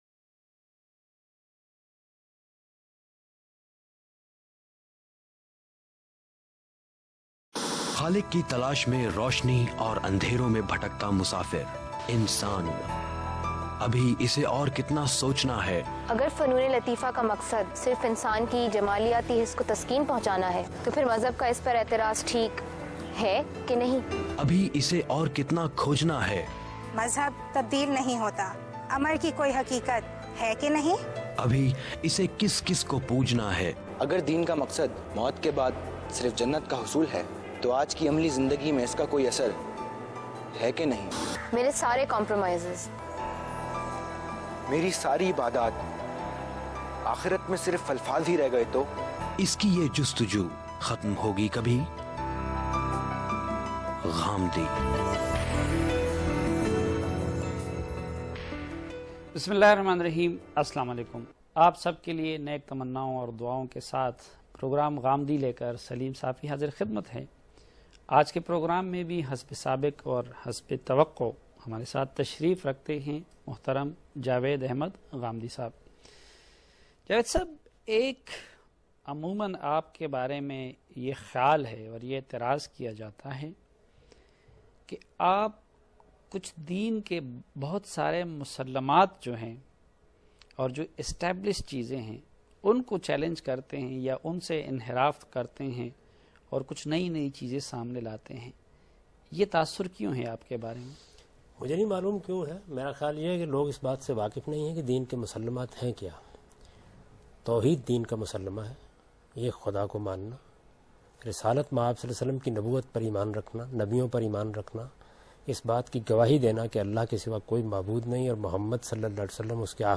Questions and Answers on the topic “Establishing Religion” by today’s youth and satisfying answers by Javed Ahmad Ghamidi.